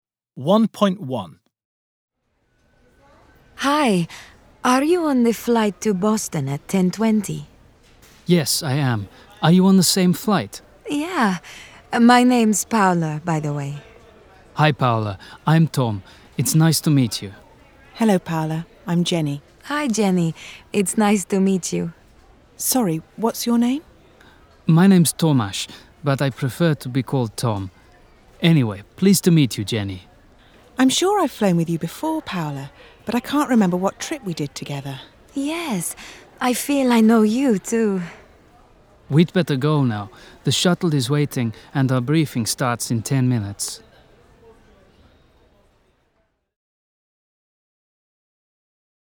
The cabin crew are meeting just before the pre-flight briefing.